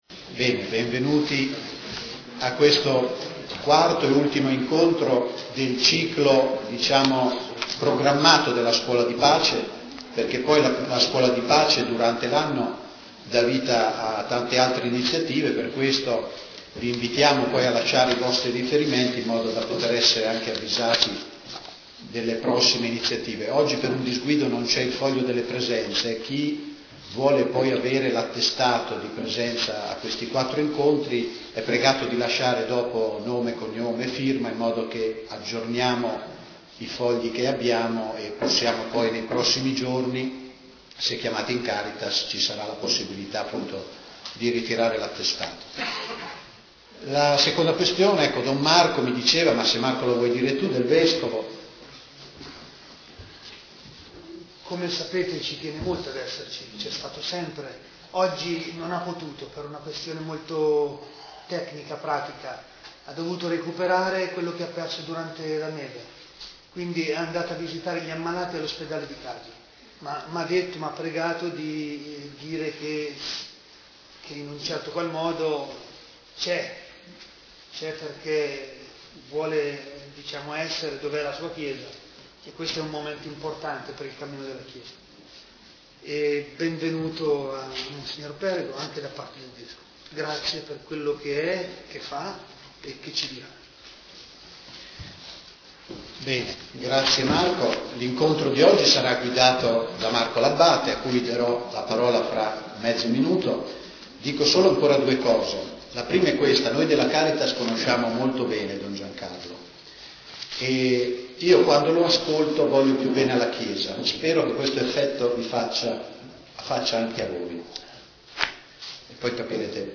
Don Giancarlo Perego alla scuola di pace